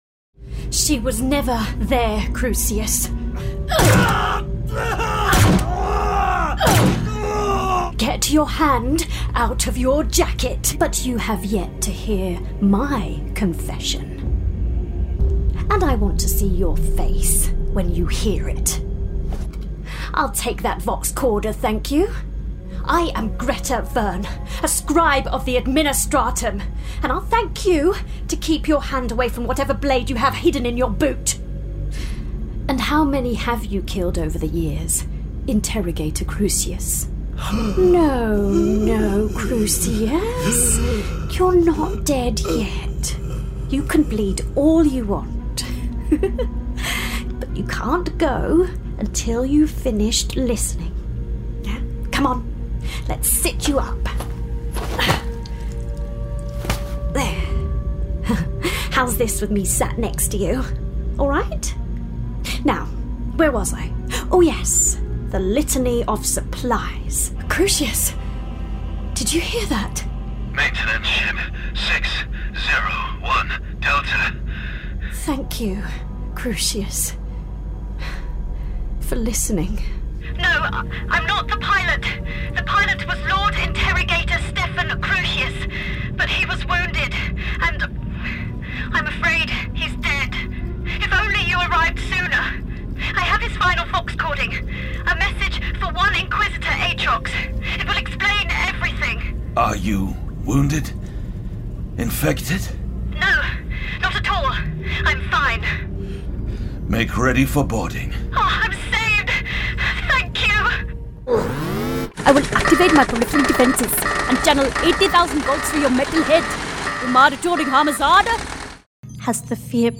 Video Game Reel
• Native Accent: London
Fresh, crisp and youthful
Based in London with a great home studio.